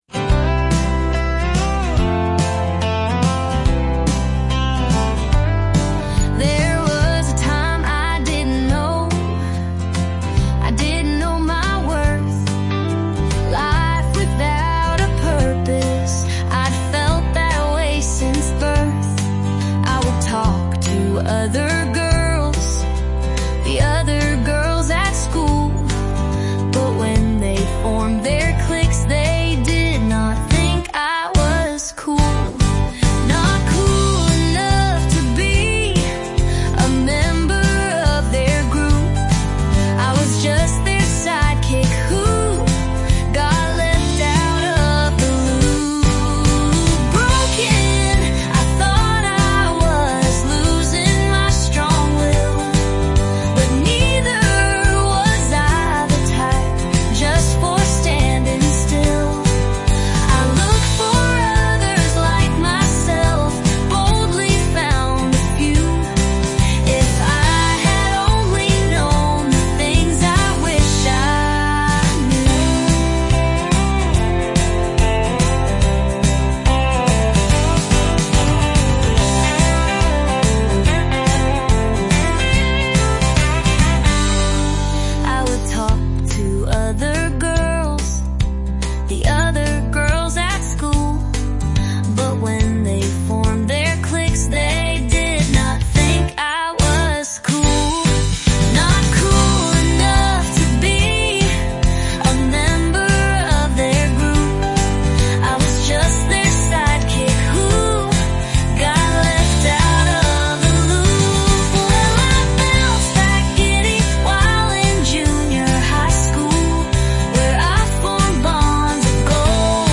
for putting this with a tune and singer!